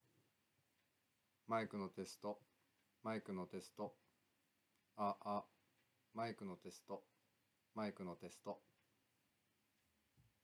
撮影環境
天候：晴れ、気温18℃、北西の風1～2m/s（微風）
装備品：ミニ三脚、ウィンドスクリーン
【CM-600：正面5cm】
それに対し「CM-600」は、雑音やビビりが少なくクリアな集音。
ほぼ無風に近かったため、風切り音をそこまで試せてはいないものの、車の往来等なにかしらの環境音は常に聞こえる状況で録音しています。